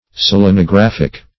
Search Result for " selenographic" : The Collaborative International Dictionary of English v.0.48: Selenographic \Sel`e*no*graph"ic\, Selenographical \Sel`e*no*graph"i*cal\, a. [Cf. F. s['e]l['e]nographique.] Of or pertaining to selenography.